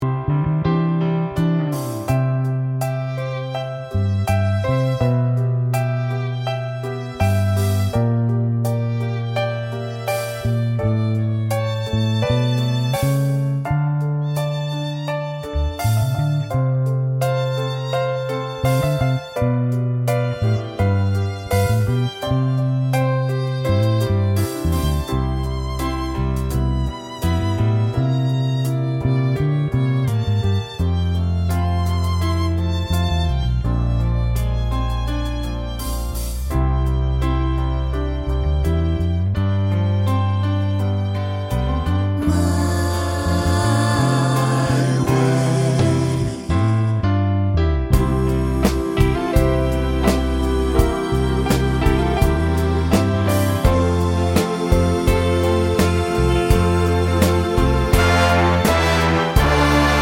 Live Version Pop (1960s) 3:48 Buy £1.50